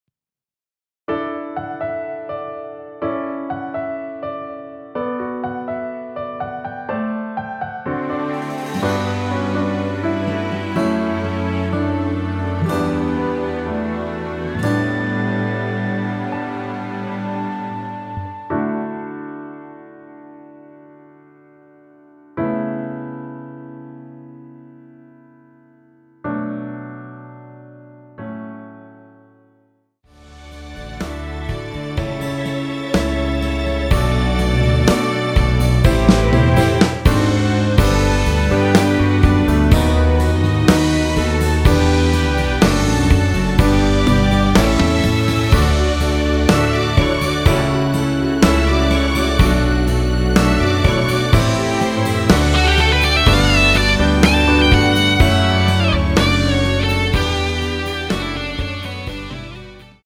원키에서(+1)올린 MR입니다.
D
앞부분30초, 뒷부분30초씩 편집해서 올려 드리고 있습니다.
중간에 음이 끈어지고 다시 나오는 이유는